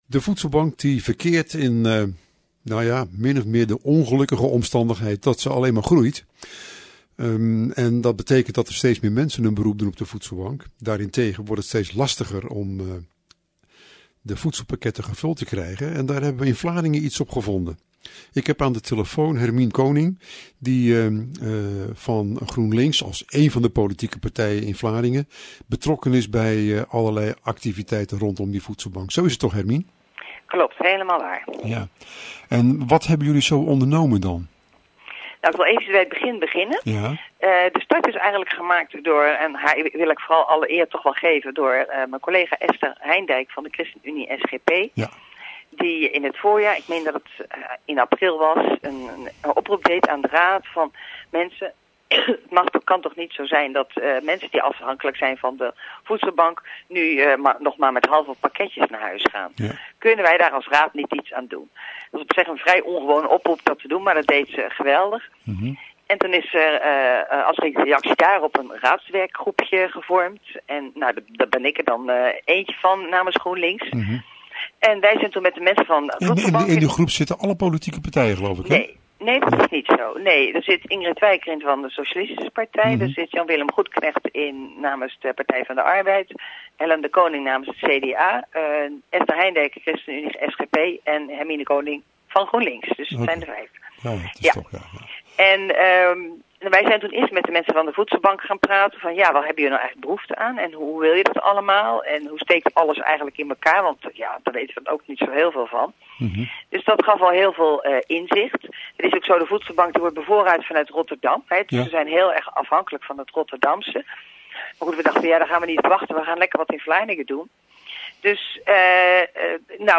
GroenLinks raadslid Hermine Koning was, als lid van de raadswerkgroep, vrijdag te horen bij Omroep Vlaardingen over deze eerste inzameling en over de manieren waarop deze actie een follow-up krijgt.